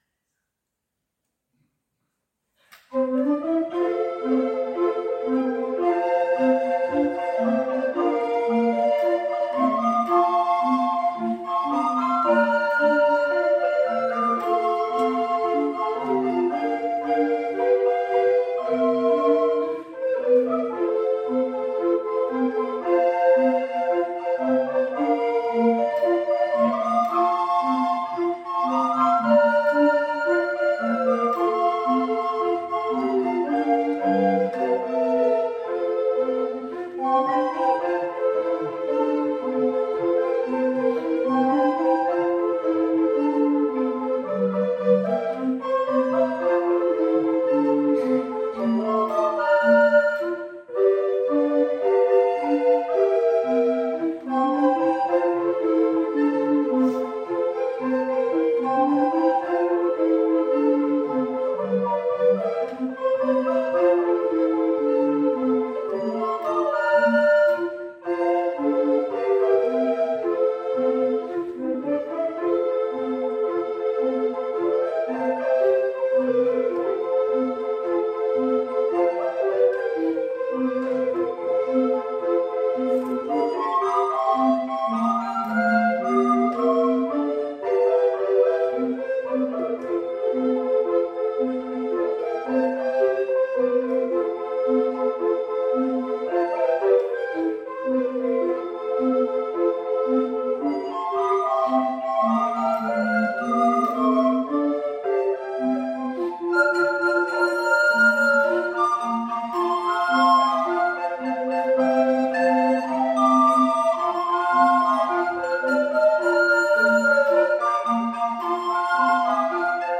Hier können Sie gern einige Klangeindrücke unseres Ensembles gewinnen: